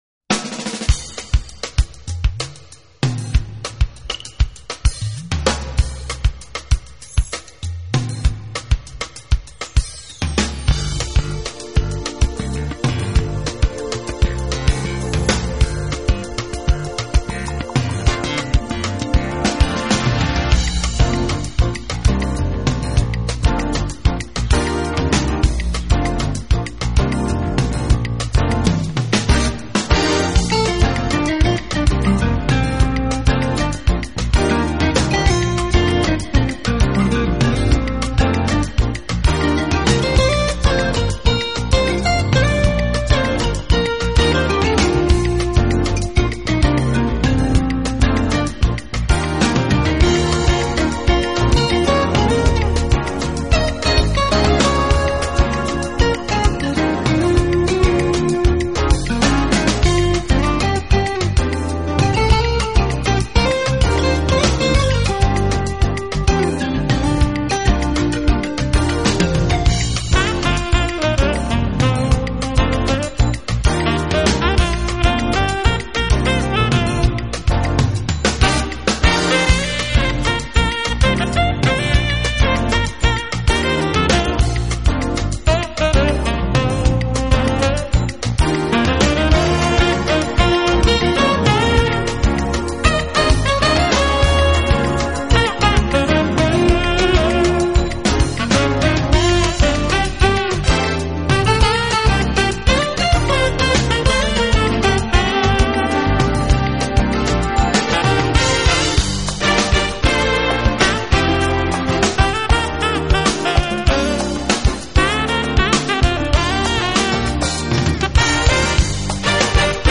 音乐类型: Smooth Jazz